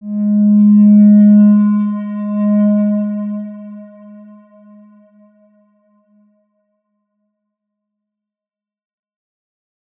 X_Windwistle-G#2-mf.wav